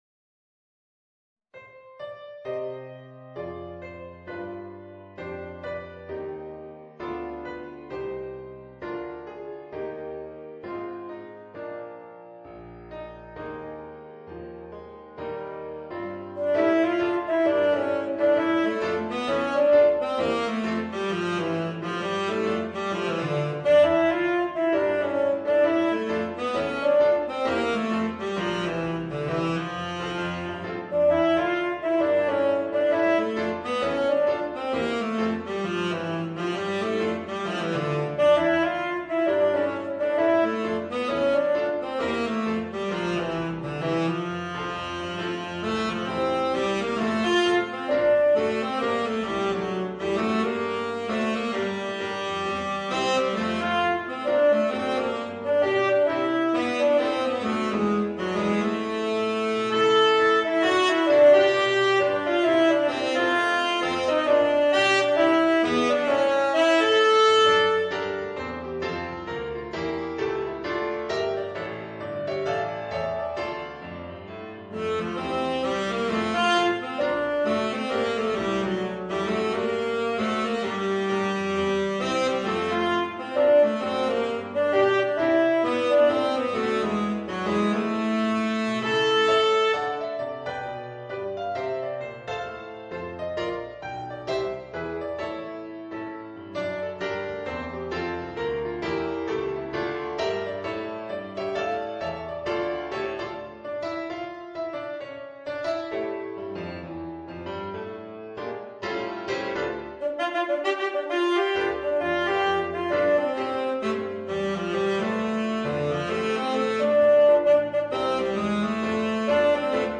Voicing: Tenor Saxophone and Piano